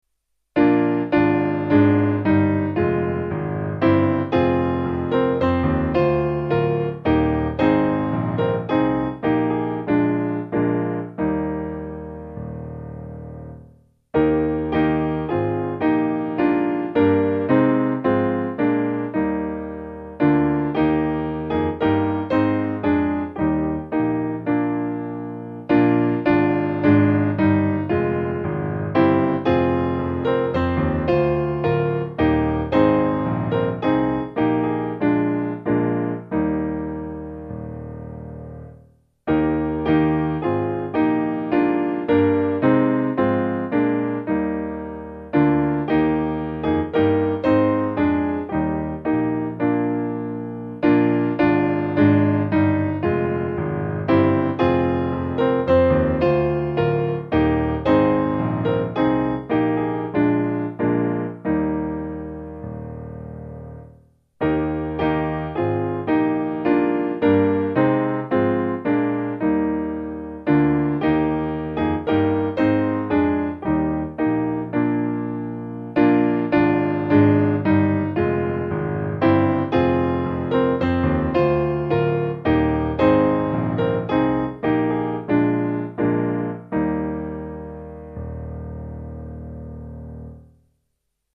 Simple Piano